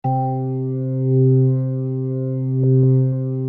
B3LESLIE C 4.wav